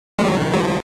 Archivo:Grito de Psyduck.ogg